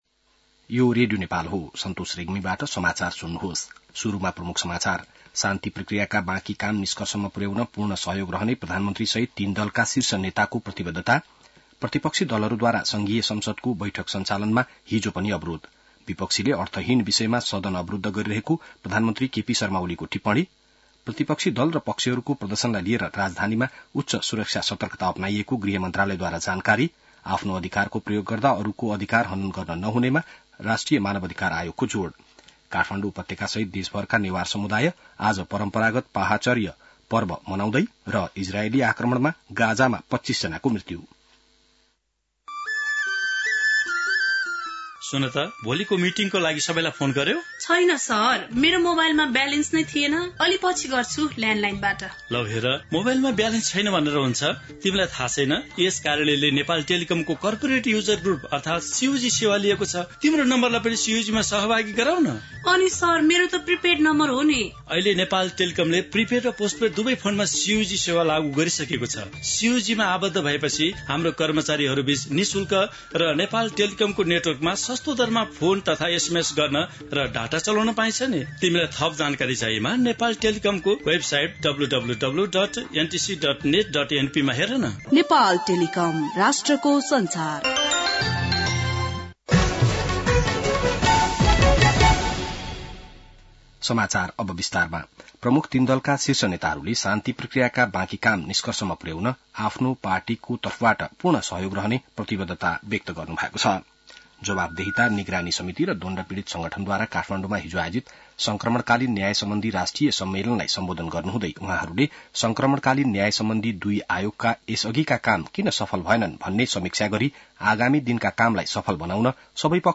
बिहान ७ बजेको नेपाली समाचार : १५ चैत , २०८१